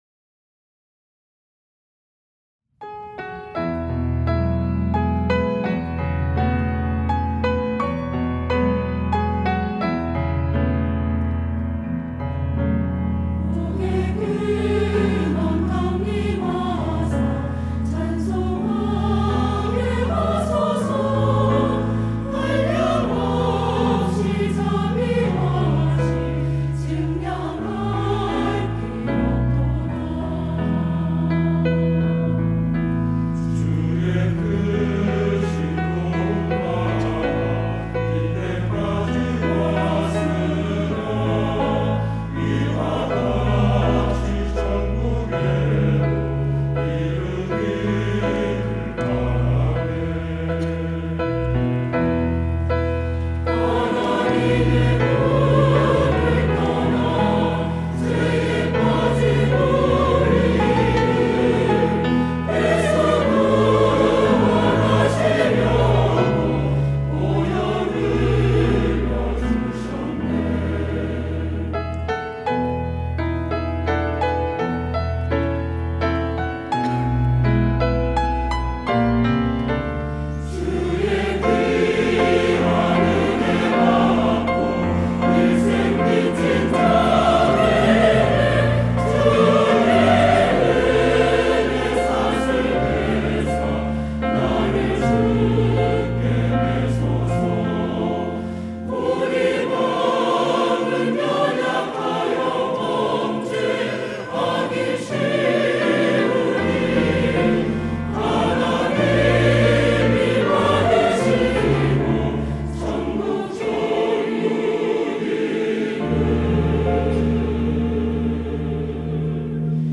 시온(주일1부) - 복의 근원 강림하사
찬양대